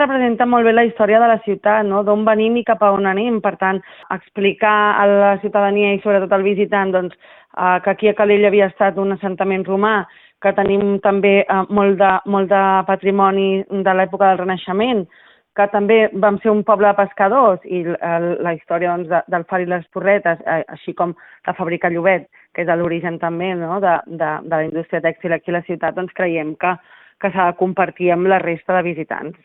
La tinent d’Alcaldia de Turisme, Cindy Rando, ressalta la importància de dotar-se d’eines innovadores per divulgar la història local, també com un complement a l’oferta per als visitants.